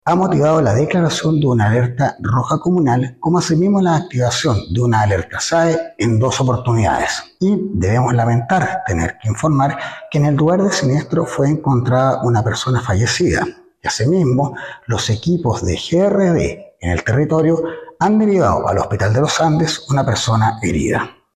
Además del daño estructural, el Director Regional de Senapred, Christian Cardemil, confirmó el fallecimiento de una persona producto del siniestro.